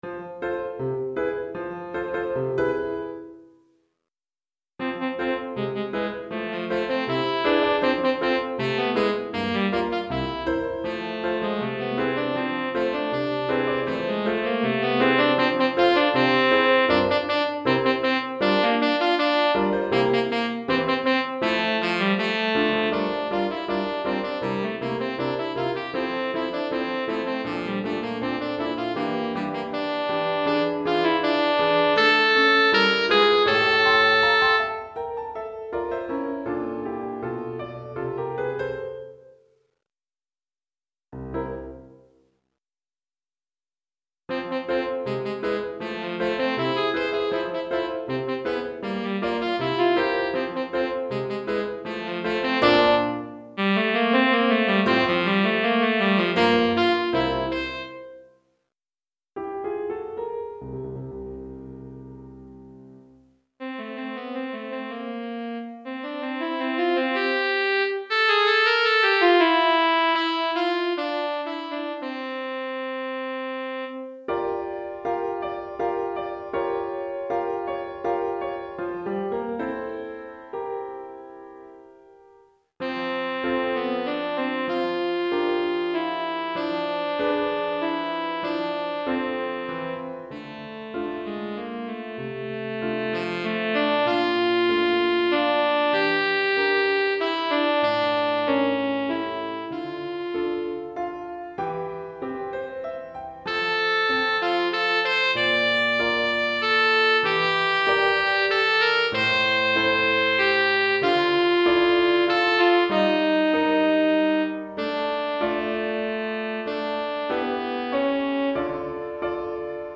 Saxophone Soprano ou Ténor et Piano